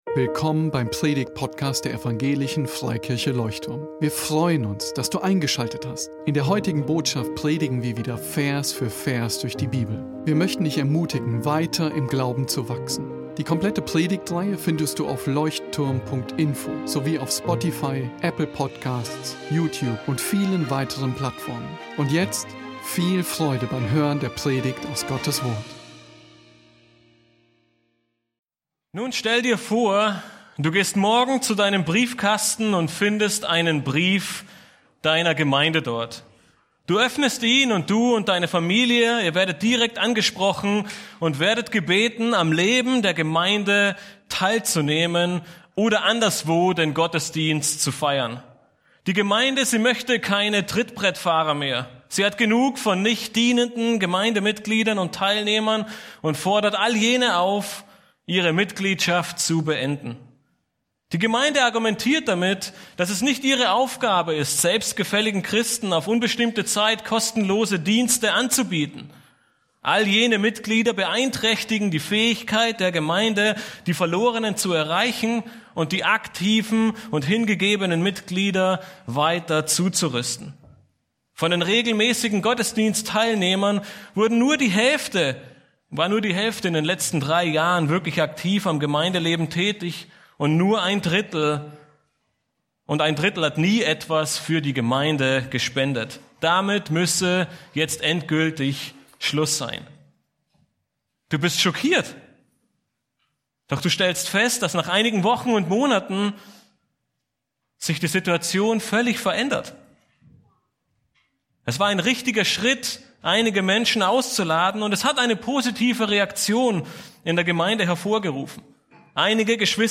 PREDIGTGLIEDERUNG 1.